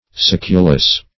Succulous \Suc"cu*lous\, a. Succulent; juicy.